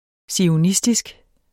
Udtale [ sioˈnisdisg ]